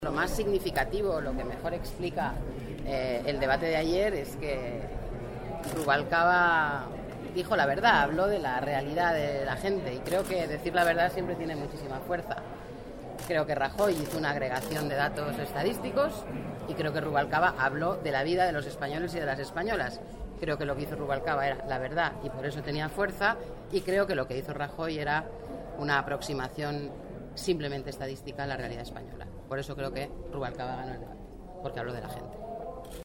Declaraciones de Elena Valenciano en los pasillos del Congreso el 26/02/2014 sobre el Debate del Estado de la Nación